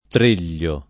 Treglio [ tr % l’l’o ]